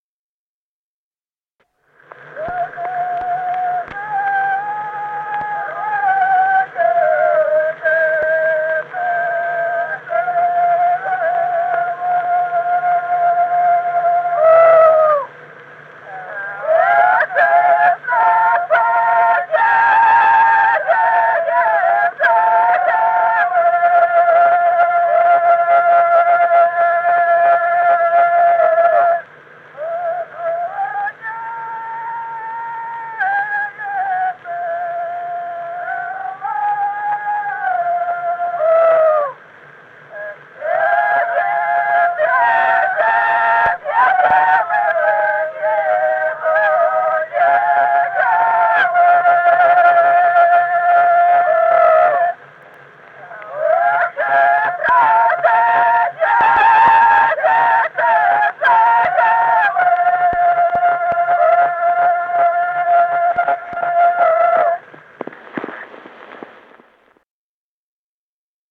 Народные песни Стародубского района «Да за горою жито жала», жнивная.
с. Остроглядово.